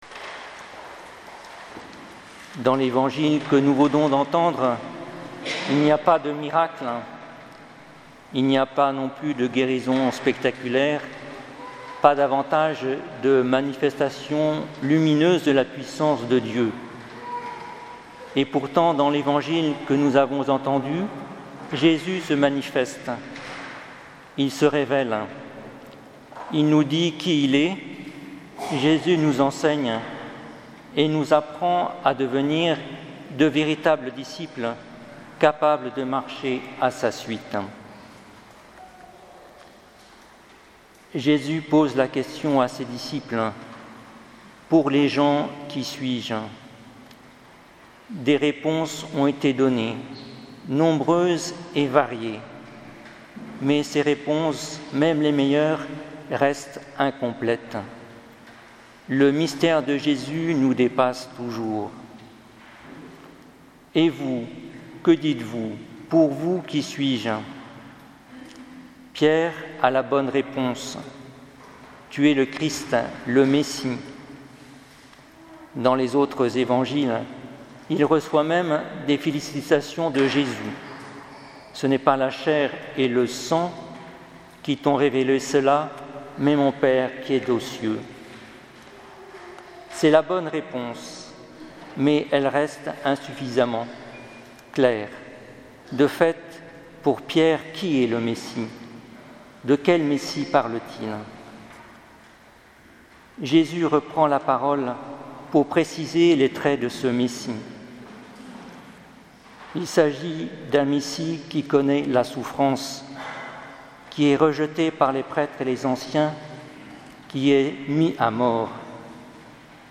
Homélie du 24ème dimanche du Temps Ordinaire